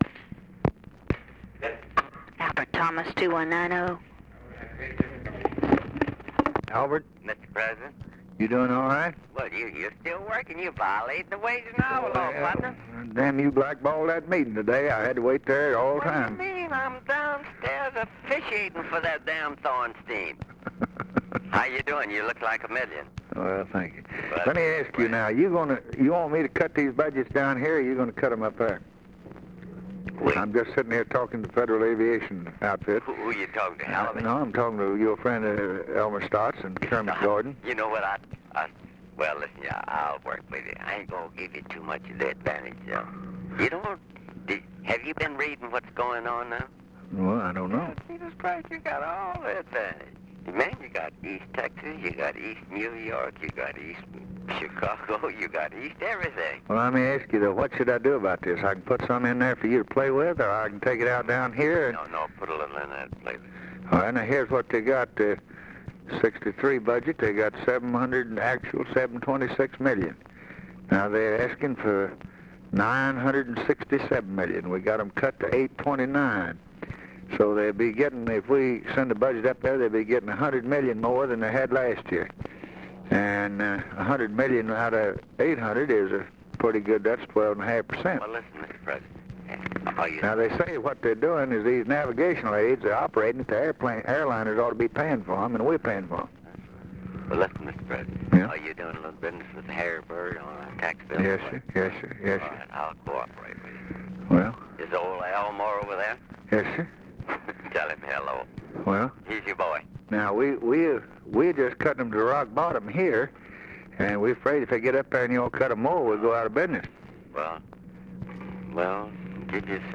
Conversation with ALBERT THOMAS, December 18, 1963
Secret White House Tapes